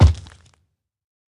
assets / minecraft / sounds / mob / zoglin / step5.ogg
step5.ogg